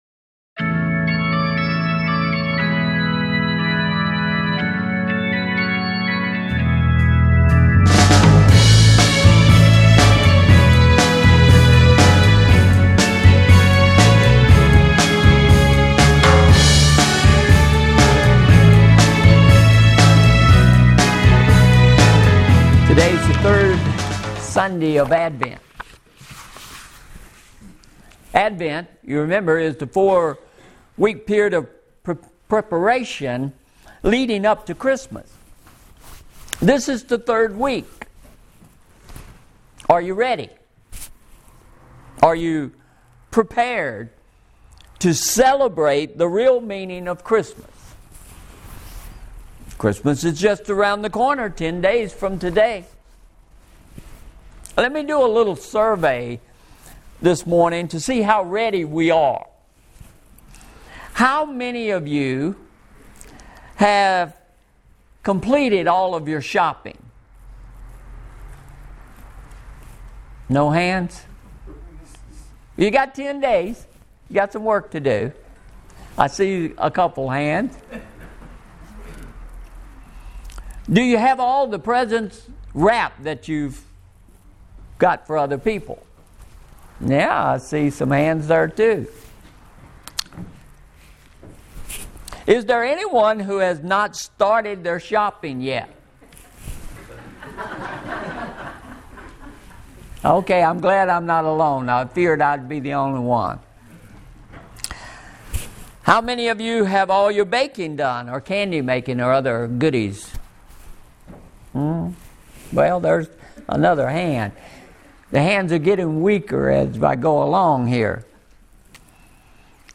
-------------------------- Sermon Synopsis -----------------------------